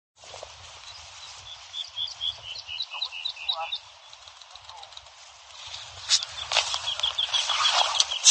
Лесной жаворонок
Песня «юли-юли-юли… юль-юль-юль…» (отсюда название).